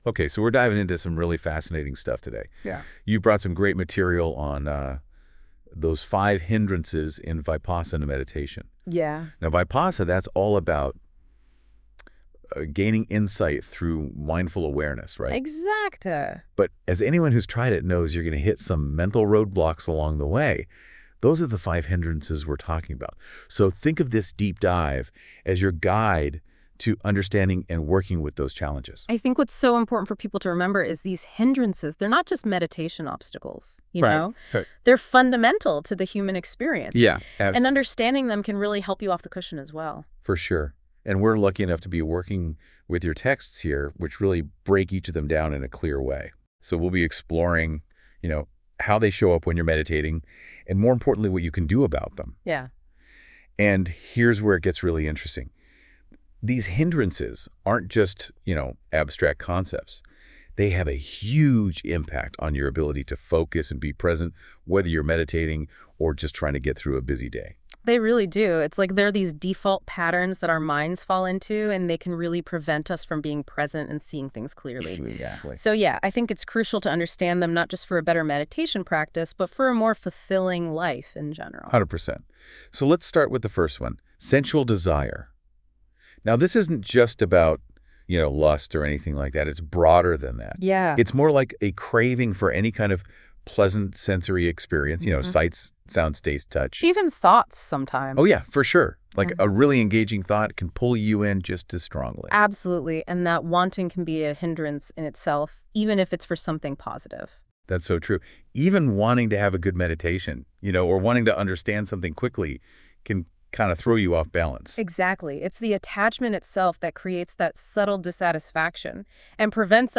I Transcribed this teaching from an audio file recording of a Dhamma Teaching by Thanissaro Bhikkhu.